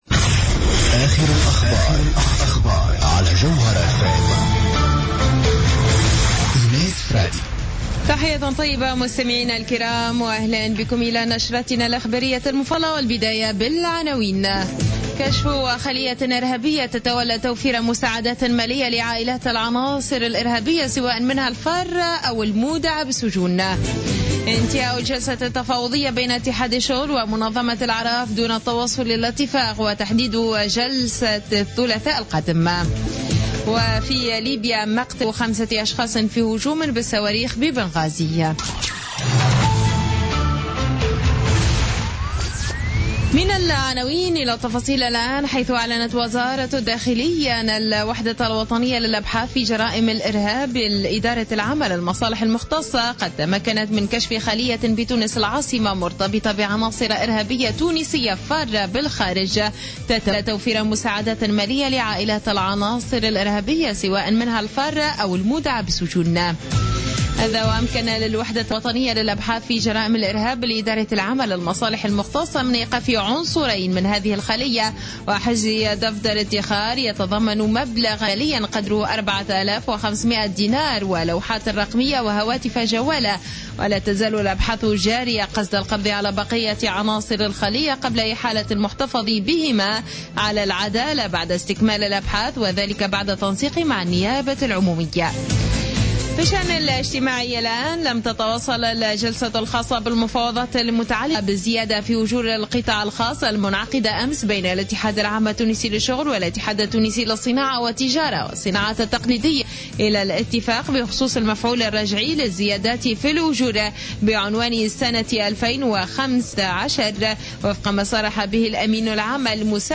نشرة أخبار منتصف الليل ليوم السبت 24 أكتوبر 2015